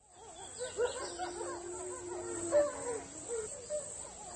ambience_wolves.ogg